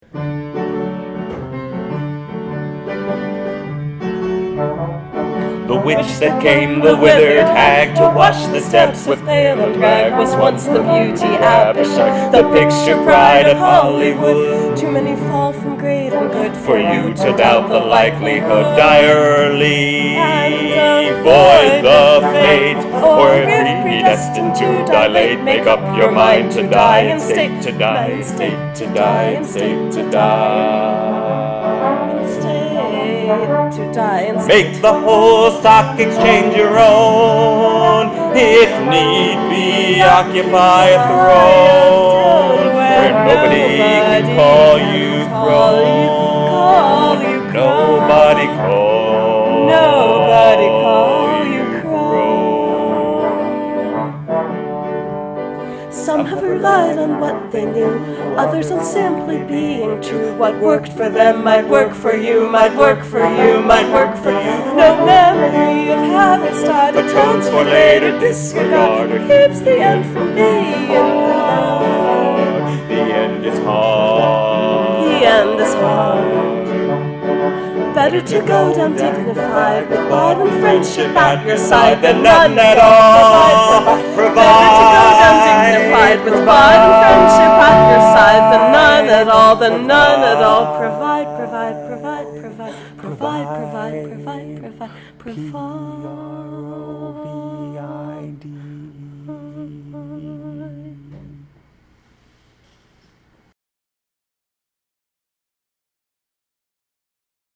Sinister, staggered chorus, piano, trombone:
The final burlesque: who wants to put on a show? And by show, I mean musical?
Recorded May 16, 2010, at the JHU Mattin Center, room 105.